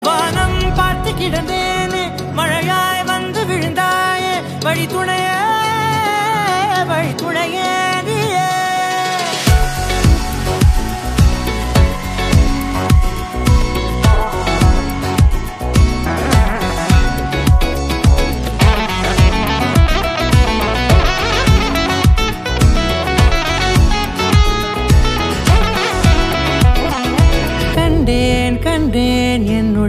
Tamil song